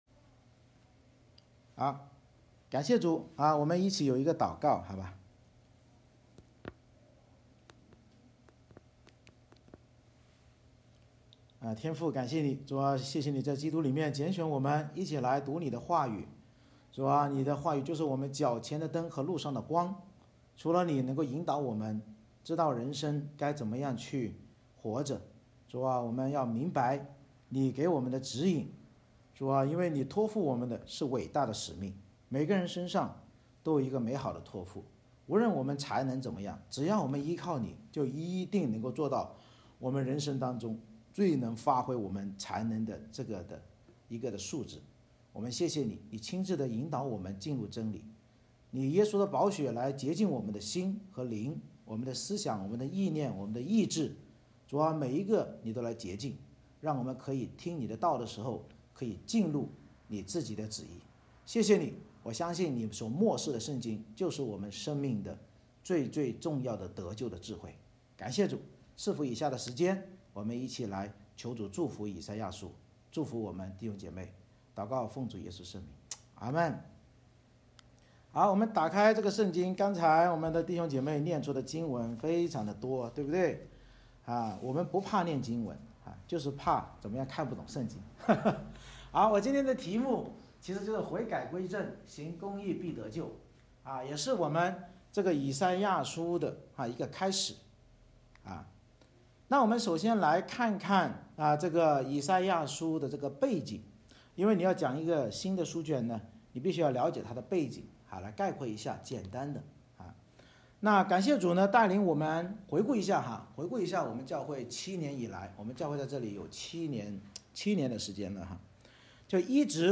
《以赛亚书》讲道系列 Passage: 以赛亚书1章 Service Type: 主日崇拜 以赛亚先知得到神呼吁以色列要悔改的默示，教导我们不要效法以色列悖逆而虚伪的宗教信仰，唯有悔改归正信靠主基督而行道才能成为得救的余民。